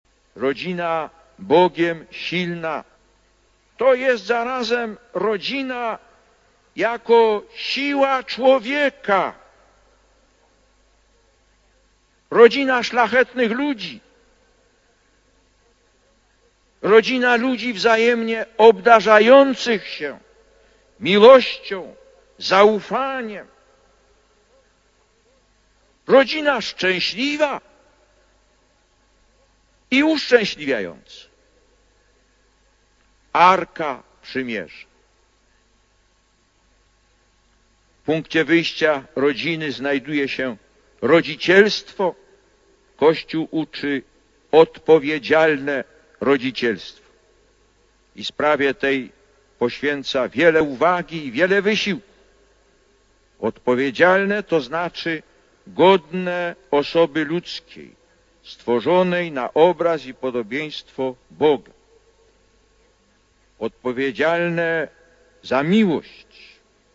Lektor: (Szczecin, 11 czerwca 1987 – nagranie): „Ewangelia dzisiejsza prowadzi nas wspólnie z Maryją i Józefem do świątyni jerozolimskiej: ofiarowanie Syna pierworodnego w czterdziestym dniu po narodzinach.
Lektor: Homilia w czasie Mszy św. dla rodzin (